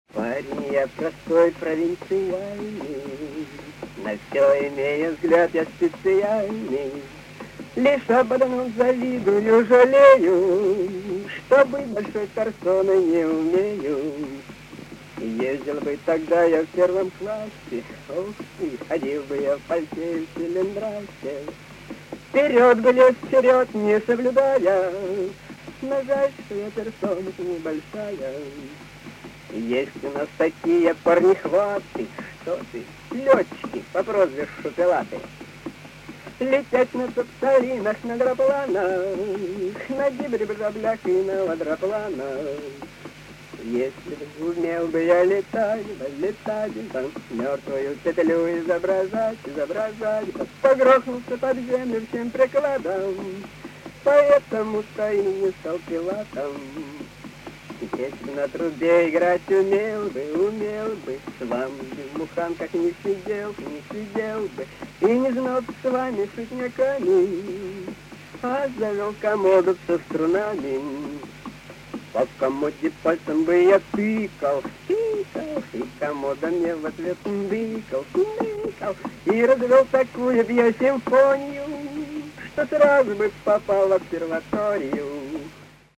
Скорость чуток уменьшил, но могу вернуть прежнюю.
poyut-studentyi-60-h---paren-ya-prostoy,-provintsialnyiy,-na-vso-imeyu-vzglyad-ya-spetsialnyiy....mp3